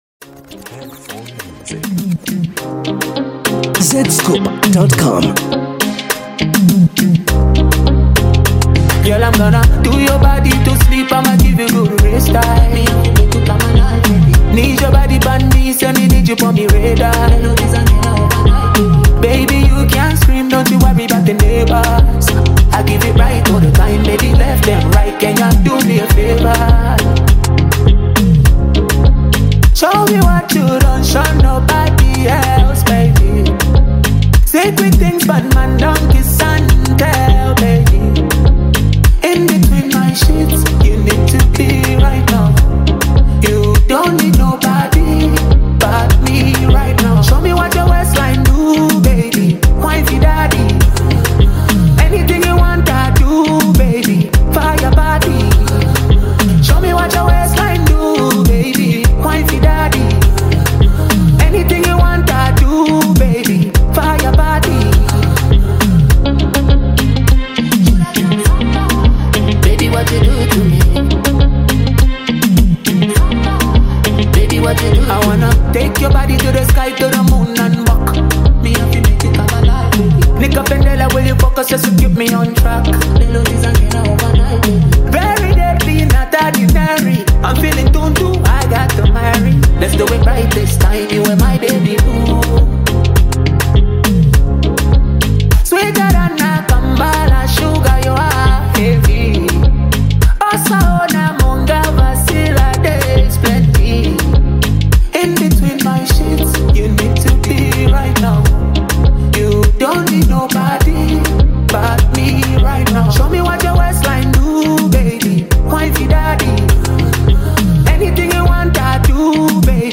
The infectious melody and heartfelt lyrics